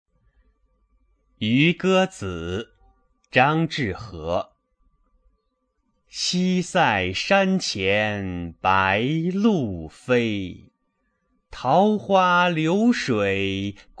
六年级语文下册课文朗读 渔歌子 2（语文a版）